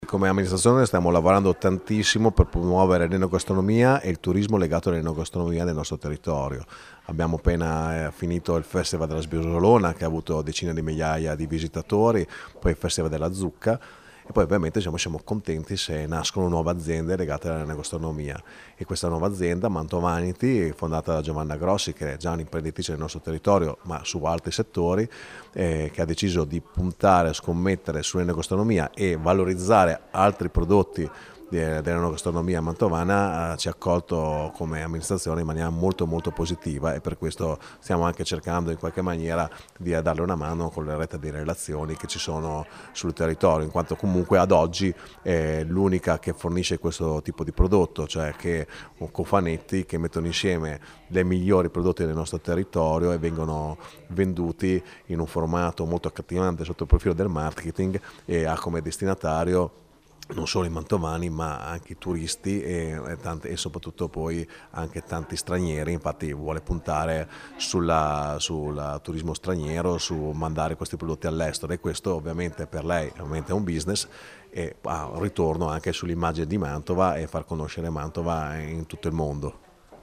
La dichiarazione dell’assessore alle attività produttive del Comune di Mantova Iacopo Rebecchi:
Assessore-Iacopo-Rebecchi-1.mp3